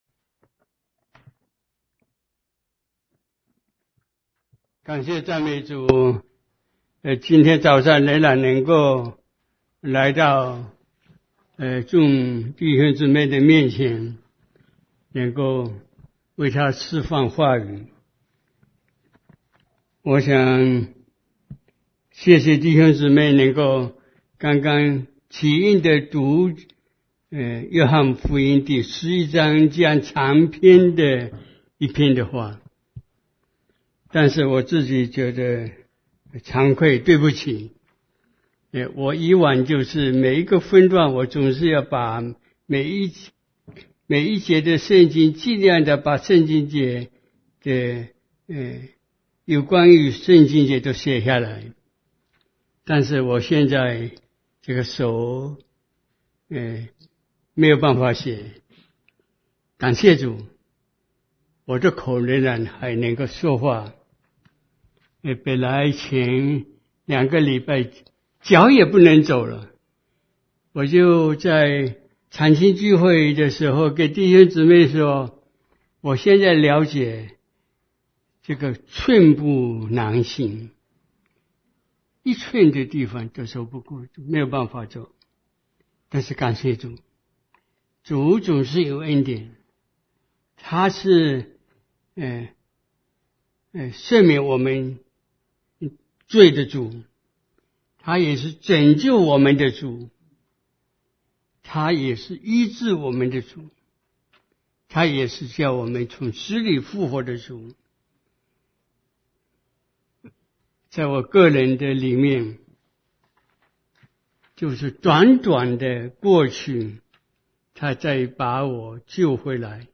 Sermon Podcasts Downloads | Greater Kansas City Chinese Christian Church (GKCCCC)